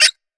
Perc 3 [ toy ].wav